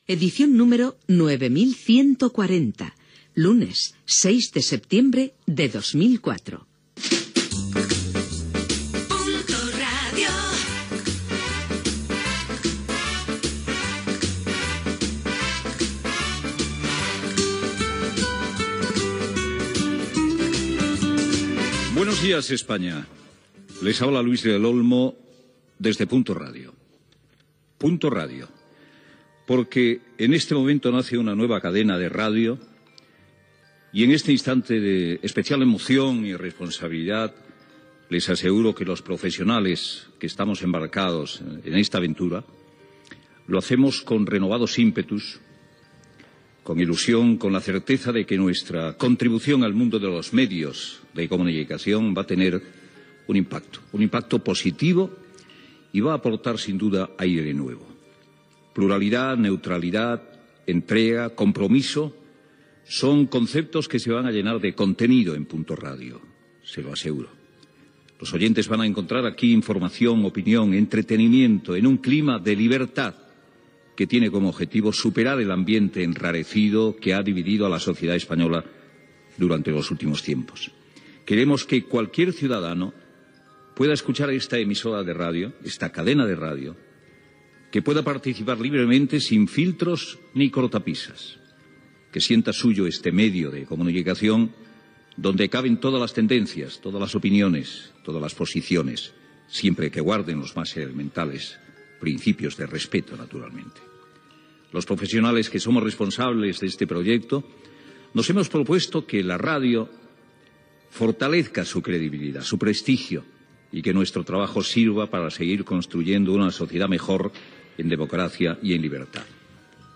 Primera salutació del programa des de la nova cadena Punto Radio. Careta i salutació
Info-entreteniment
FM